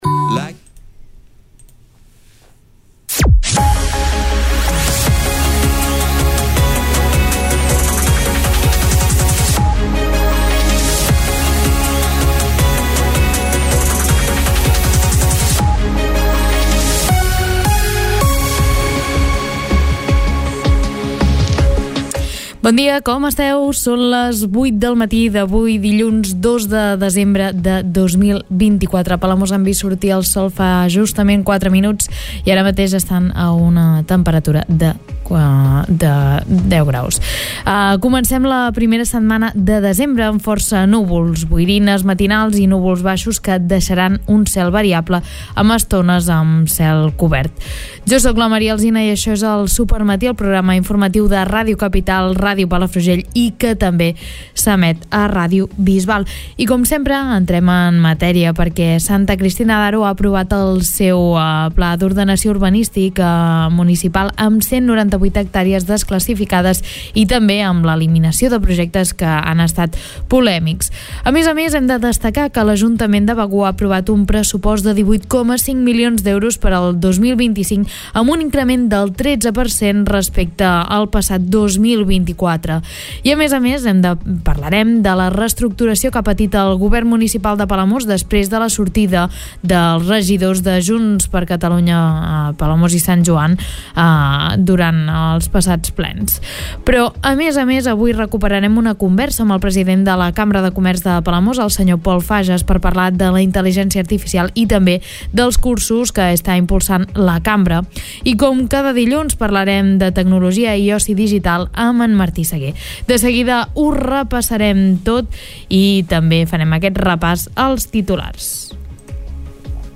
Escolta l'informatiu d'aquest dilluns